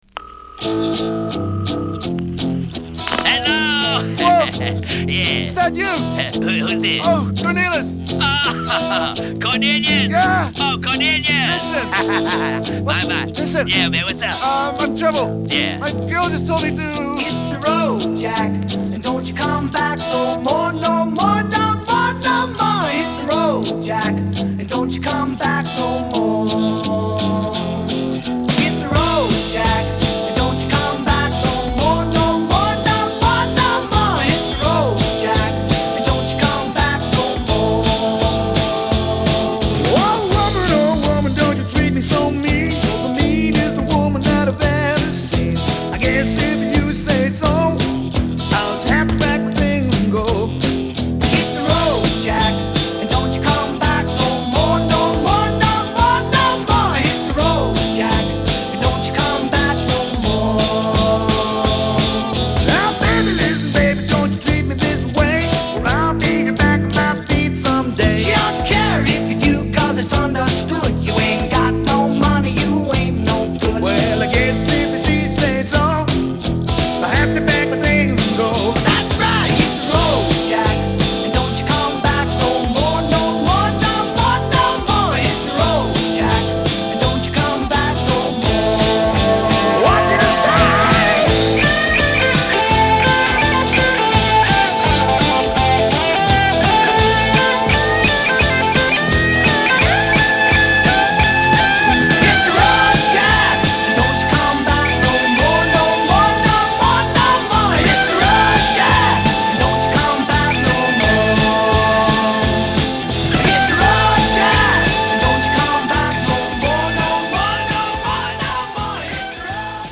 Rock / Pop canada
電話音から始まるユニークなオープニング、スウィンギン・ロックなアレンジが格好良い一曲です。